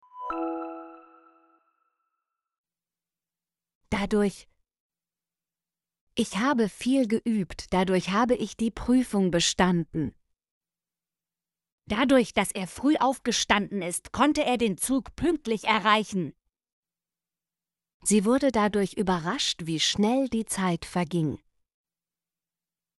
dadurch - Example Sentences & Pronunciation, German Frequency List